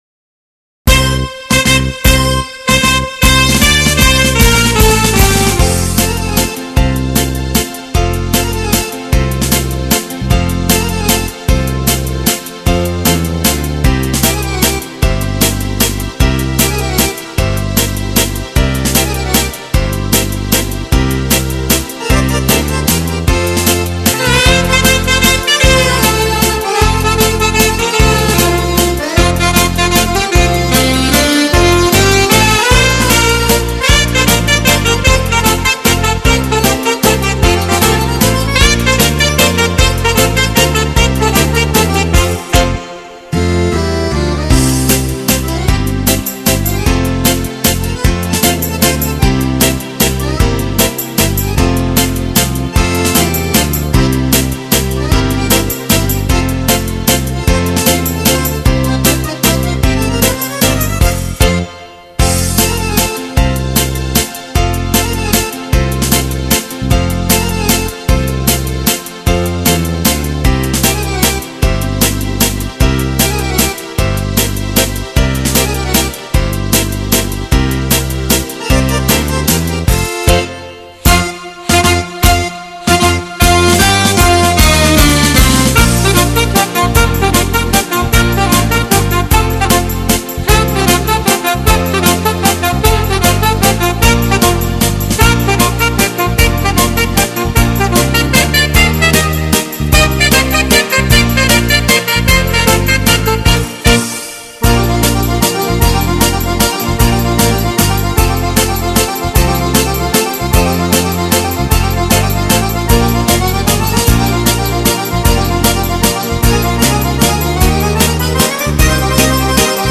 Genere: Mazurka
Scarica la Base Mp3 (2,55 MB)